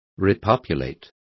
Complete with pronunciation of the translation of repopulating.